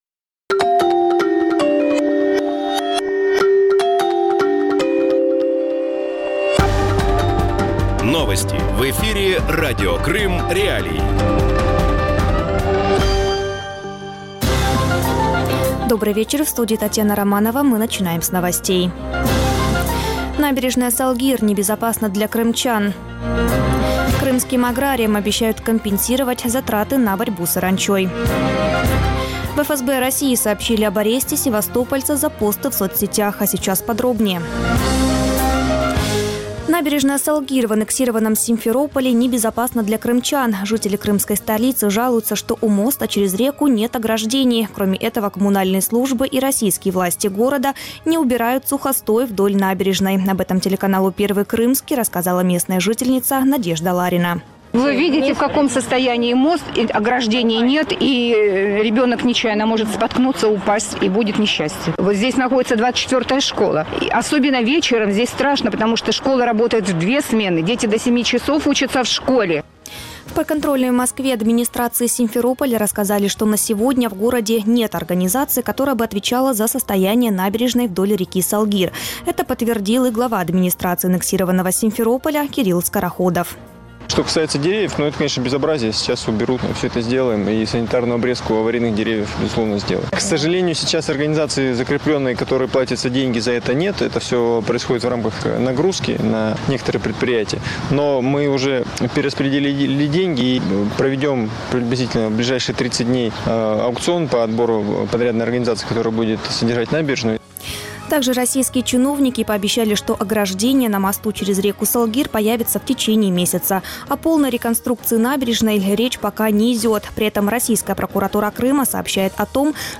Вечерний выпуск новостей о событиях в Крыму. Все самое важное, что случилось к этому часу на полуострове.